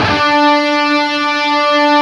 LEAD D 3 LP.wav